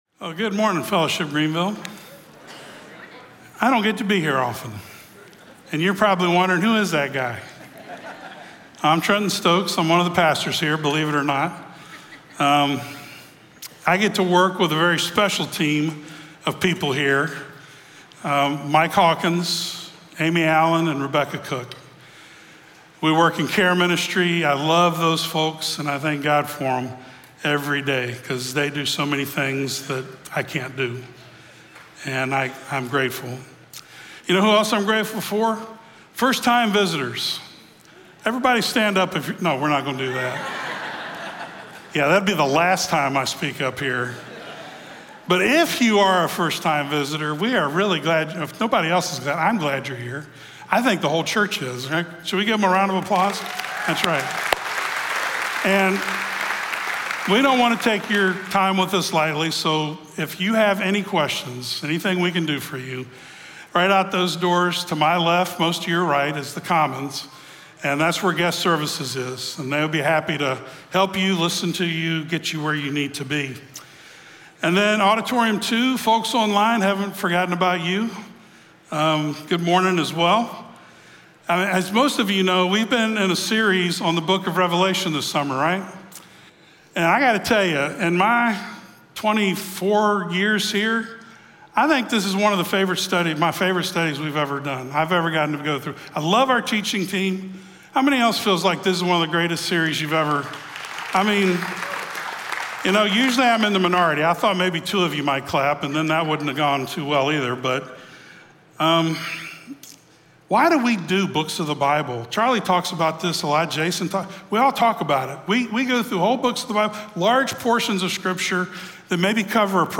Revelation 15 Audio Sermon Notes (PDF) Ask a Question Scripture: Revelation 15 SERMON SUMMARY In Revelation 15 today, we are transported to two heavenly scenes.